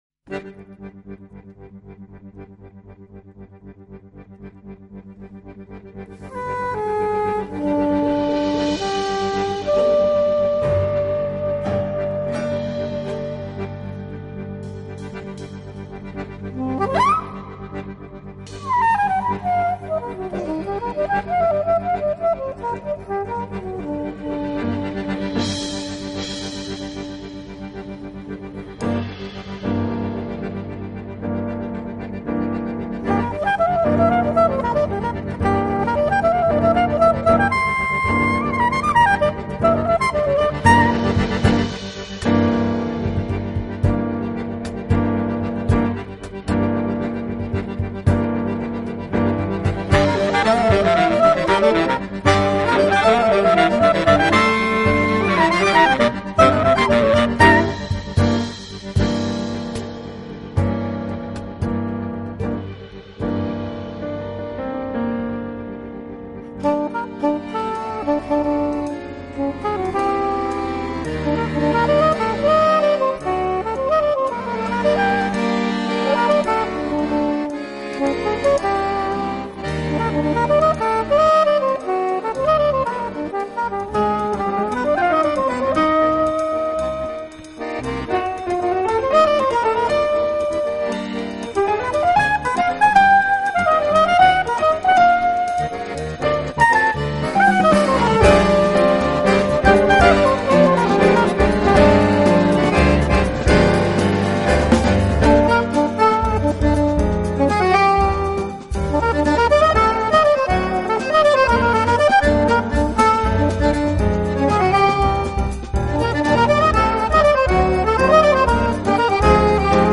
Genre........: Jazz